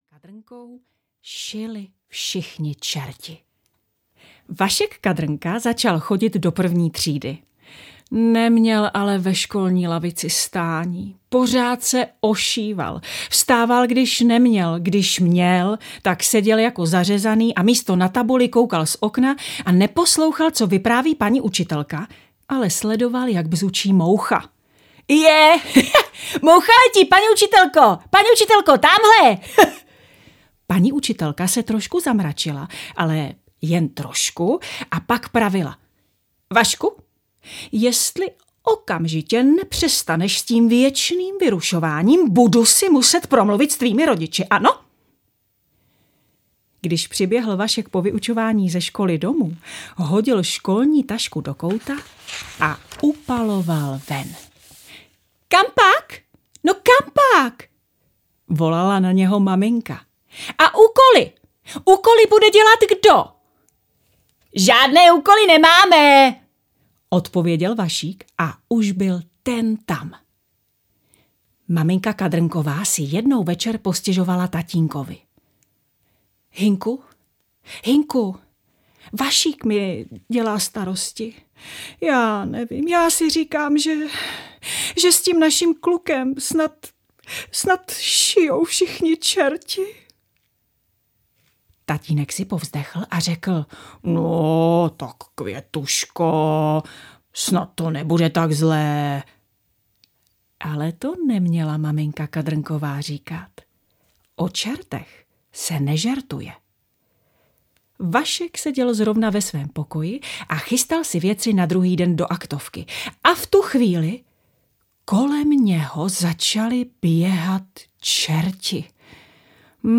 Ukázka z knihy
Nahráno ve studiu Elshis